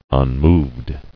[un·moved]